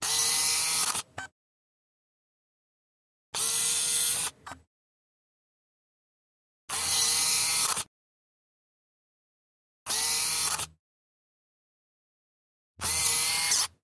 Camera Digital Servo Lens Moves x5